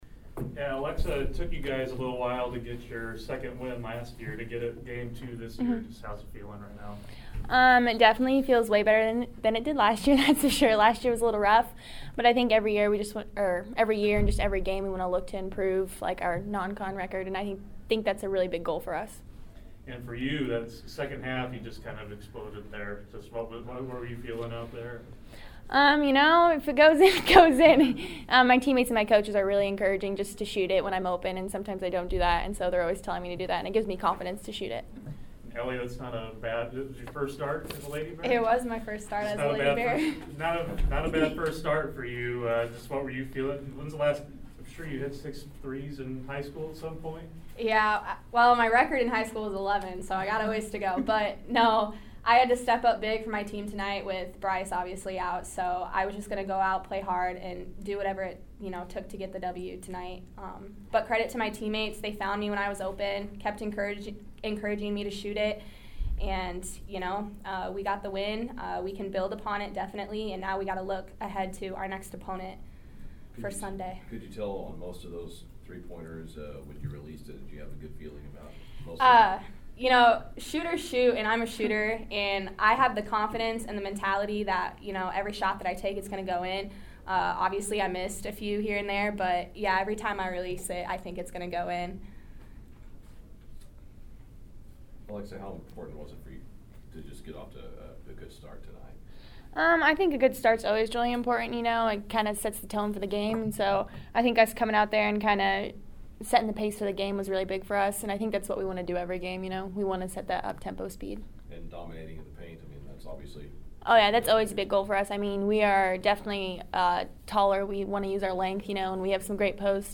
postgame press conference